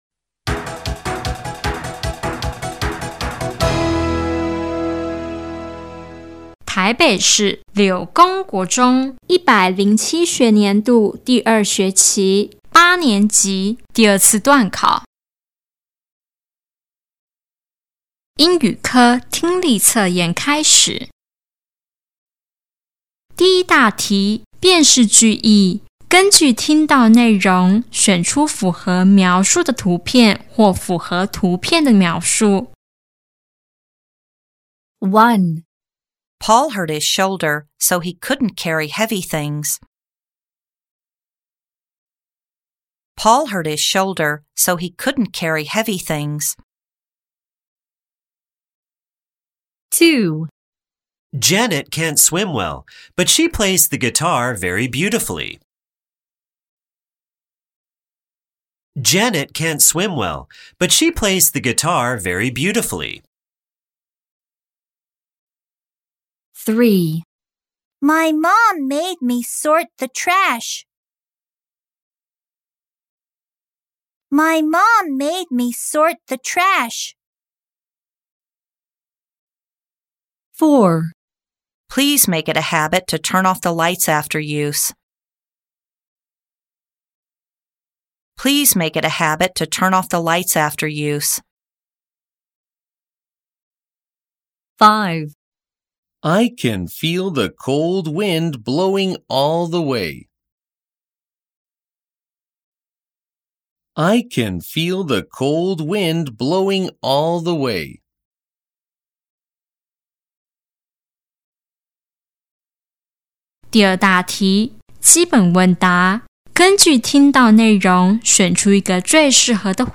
1072二段八年級英文聽力測驗.mp3